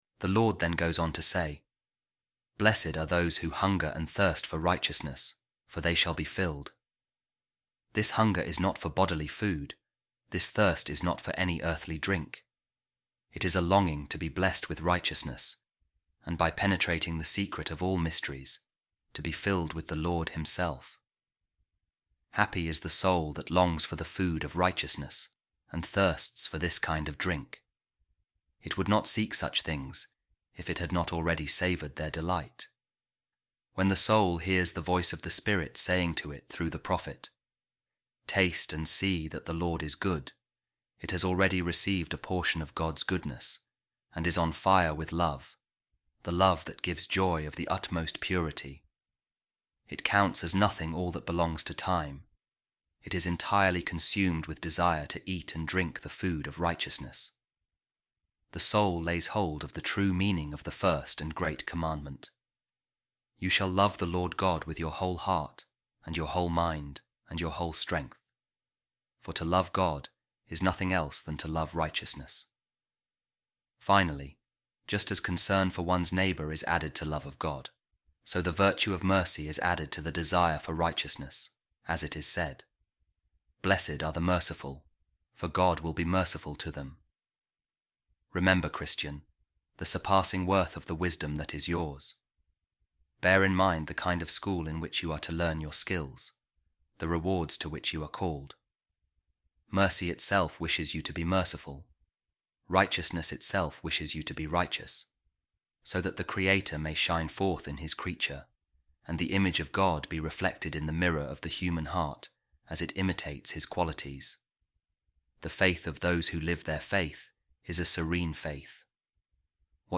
A Reading From The Sermon Of Pope Saint Leo The Great On The Beatitudes | Christian Wisdom